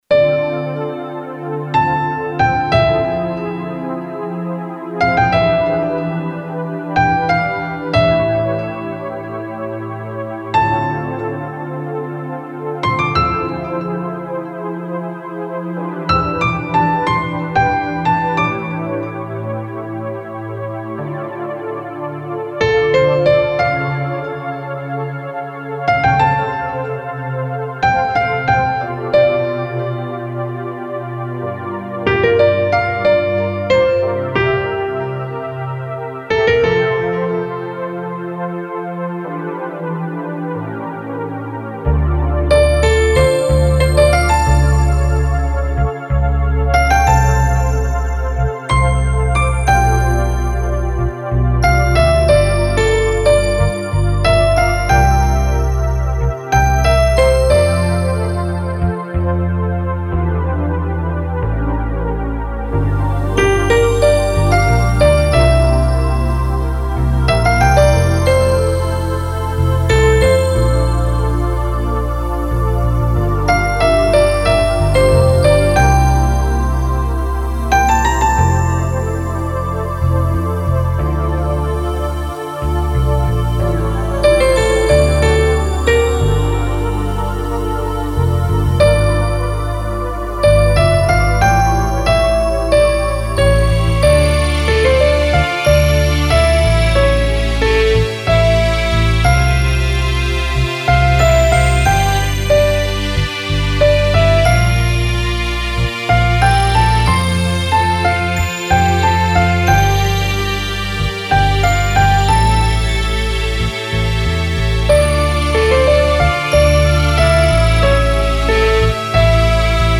フリーBGM イベントシーン 切ない・悲しい
フェードアウト版のmp3を、こちらのページにて無料で配布しています。